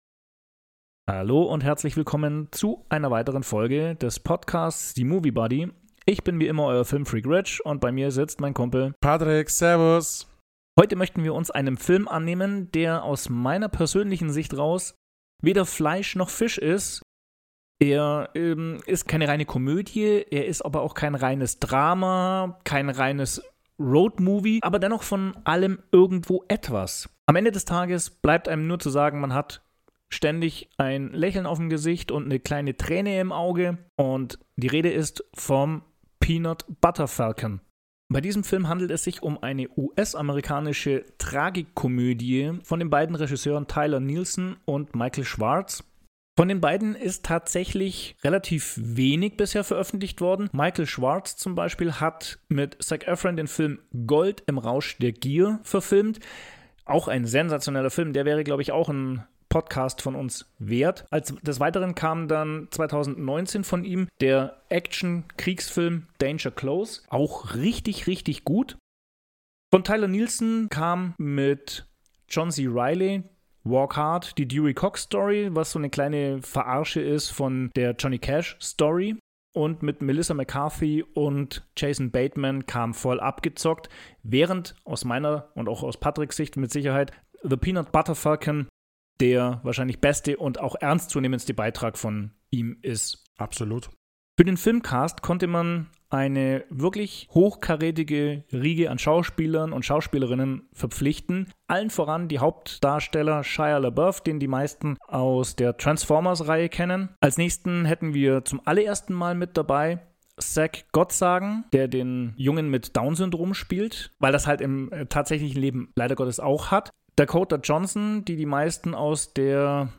Die beiden Protagonisten müssen sich auf der gemeinsamen Flucht, aus unterschiedlichsten Gründen, zusammenraufen und erfahren, wie wichtig es ist für einander da zu sein, sich nicht immer selbst in den Fokus zu stellen und das Leben nicht ständig zu ernst zu nehmen. Erlebt eine tiefgründige Unterhaltung über Normalität.